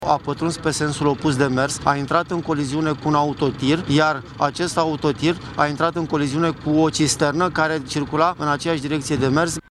20feb-08-politist-despre-accident-jandarm-Buzau.mp3